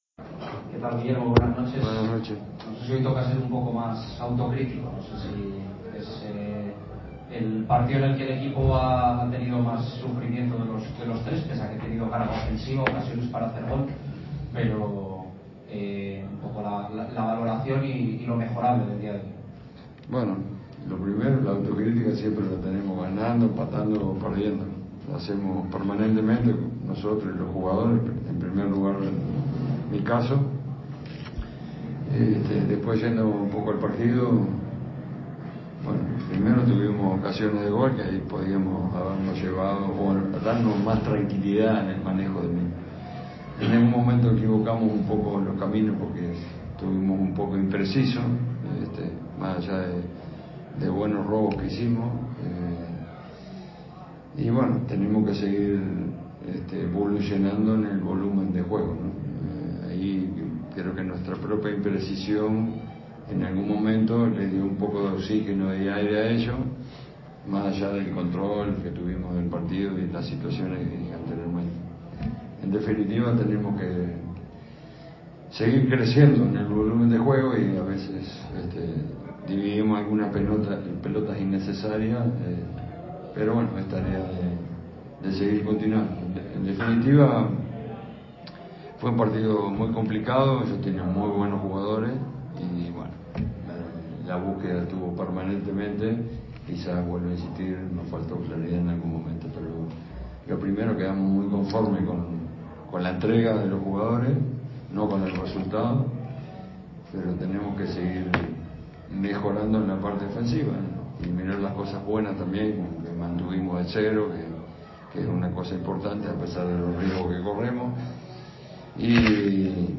Ruedas de prensa
aquí la rueda de prensa completa